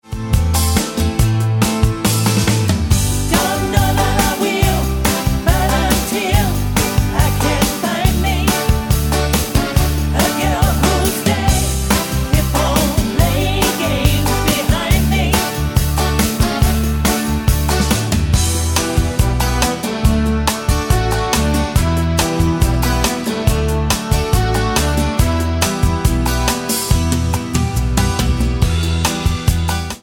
Tonart:G mit Chor
Die besten Playbacks Instrumentals und Karaoke Versionen .